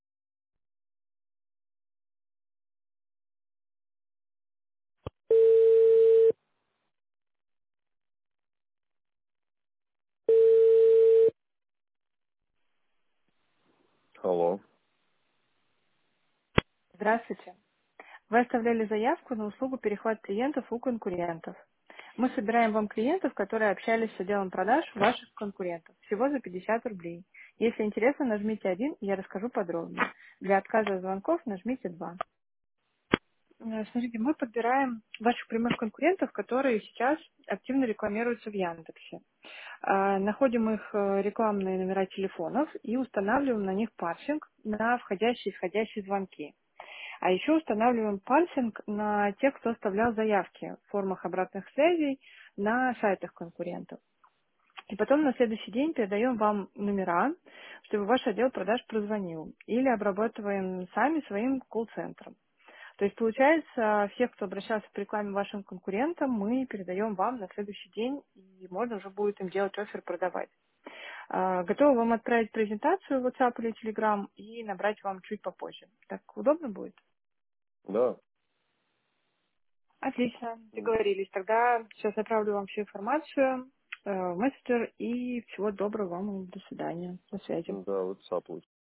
Текст озвучивается живым человеческим голосом, что вызывает доверие.
Примеры аудиозаписей "Звонка робота"